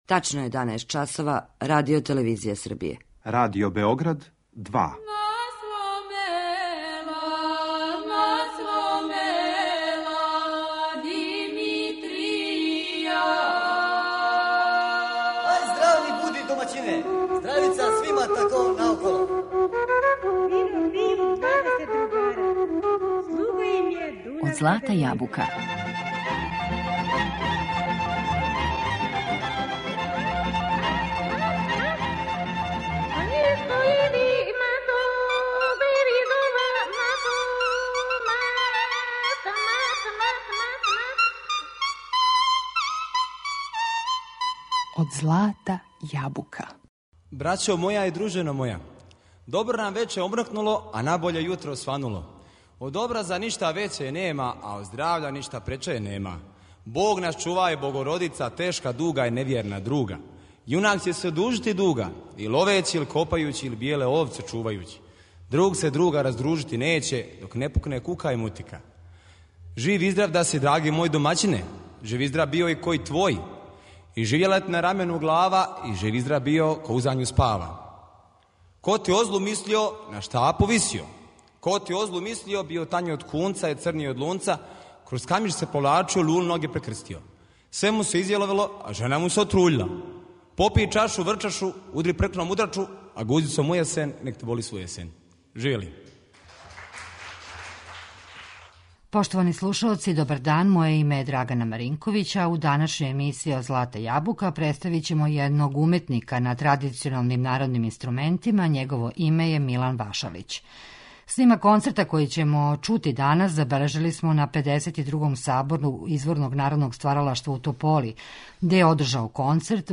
У данашњој емисији представићемо народног уметника на традиционалним дувачким инструментима
Слушамо снимак концерта који смо забележили на 52. Сабору изворног Народног стваралаштва у Тополи.